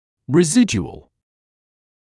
[rɪ’zɪdjuəl][ри’зидйуэл]остаточный